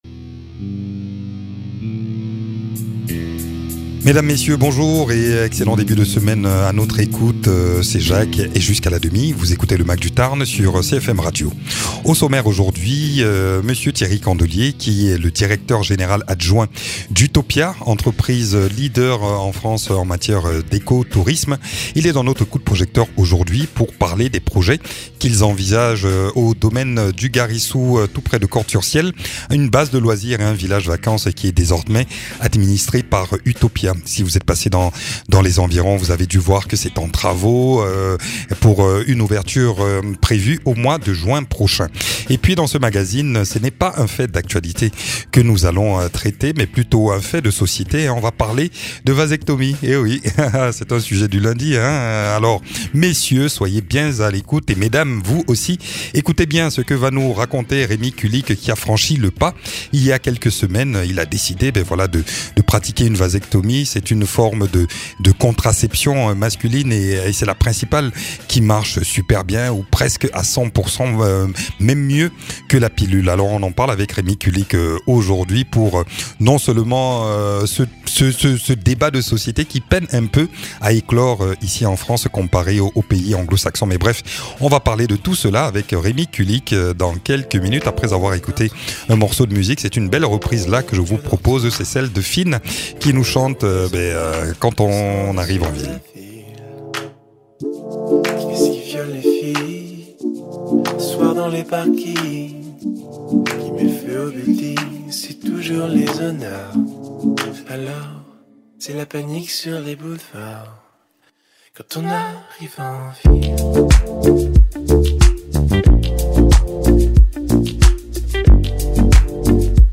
Aujourd’hui nous abordons cela avec un invité qui vient témoigner de son cas personnel suite à une vasectomie. Et puis, nous nous intéressons au camping qui ouvrira ses portes fin juin près de Cordes-sur-ciel.